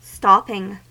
Ääntäminen
US : IPA : [ˈstɑp.ɪŋ]